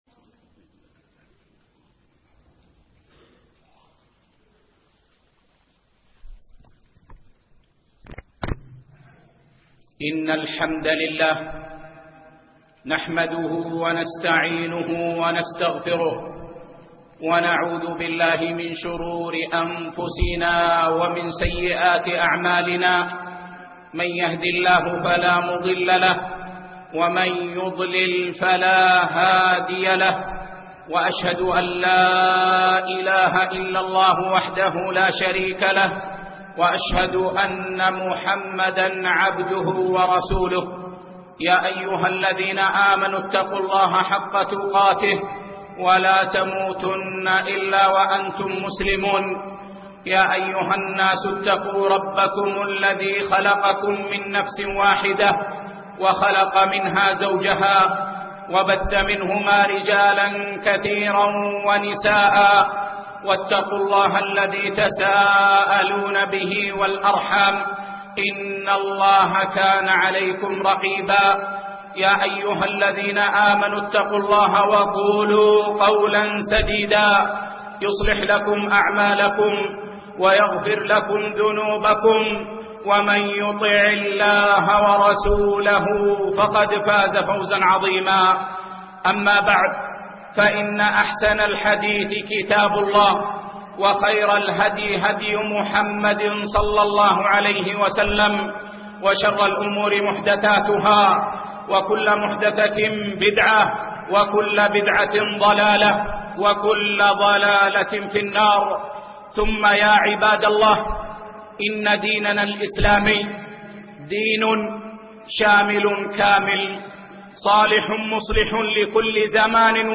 أهمية اجتماع المسلمين - خطبة